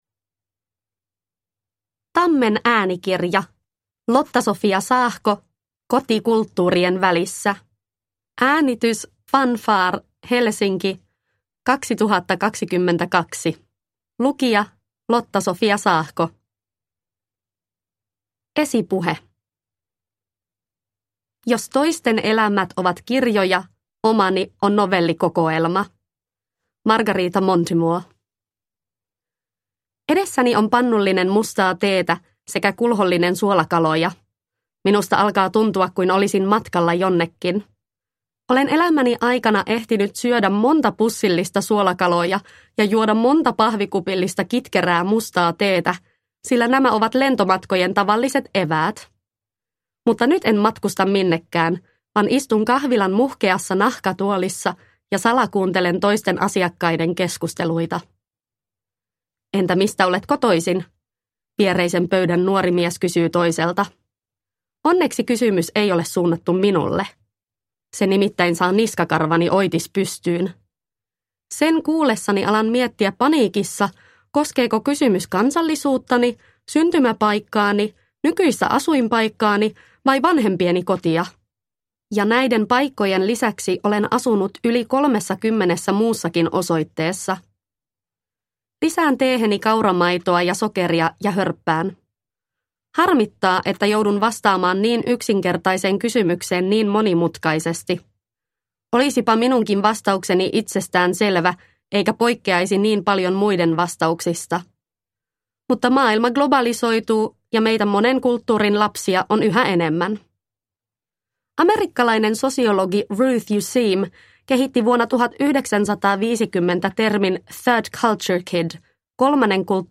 Koti kulttuurien välissä – Ljudbok – Laddas ner